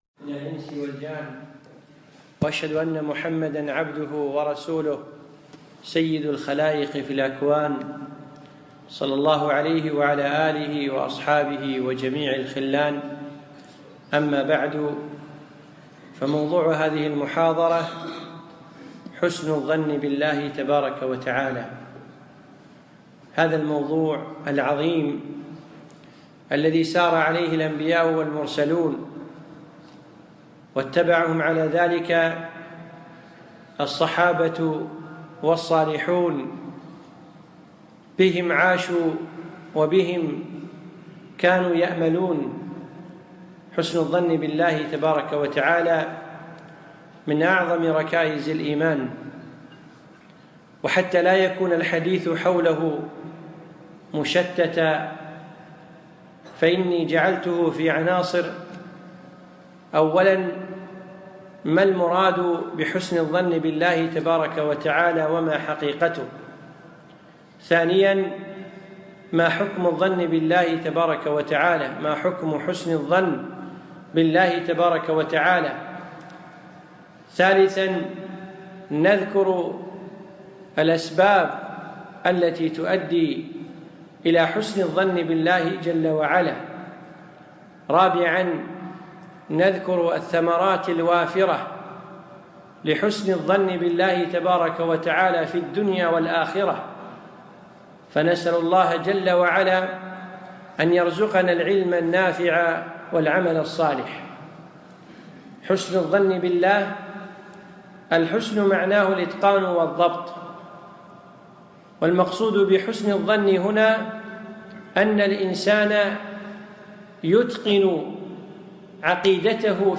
تم القاء هذه المحاضره يوم الأربعاء 8 / 4/ 2015 في مسجد زيد بن الحارثة في منطقة سعد العبدالله